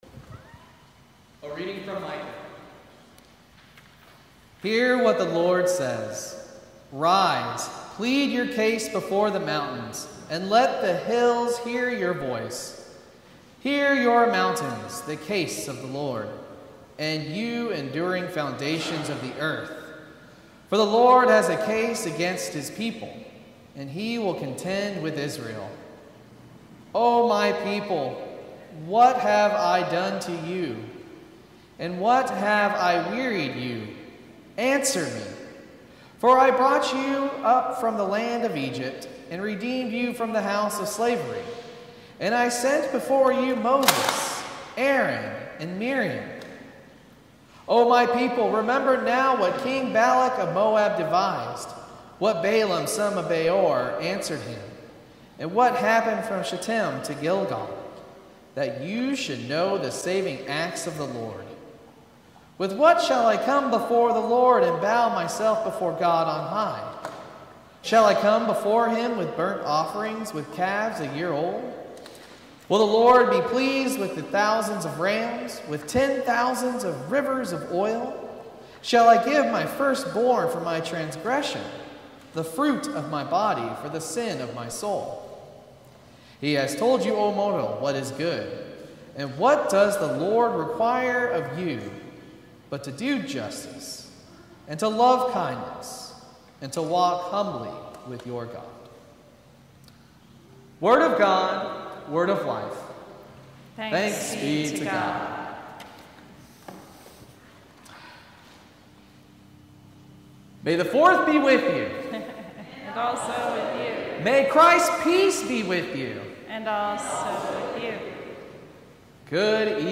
Sermon from May the Fourth Service